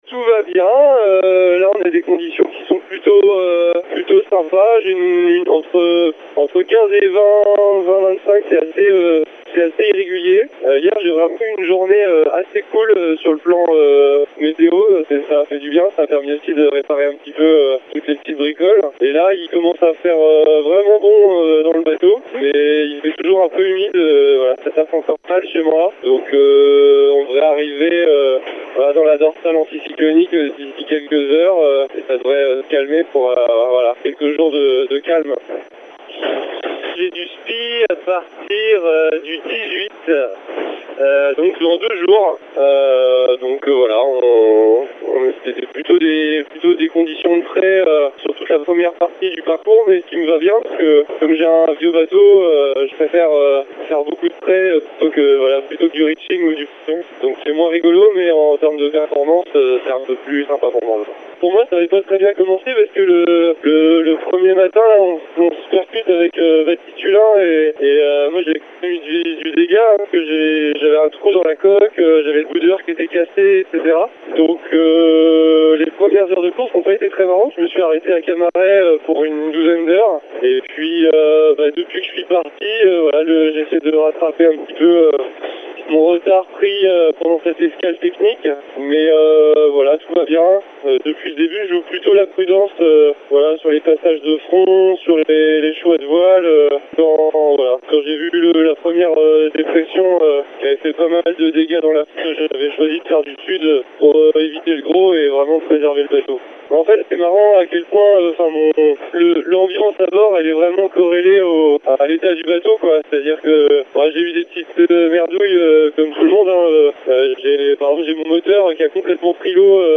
Voici des nouvelles directement du bateau.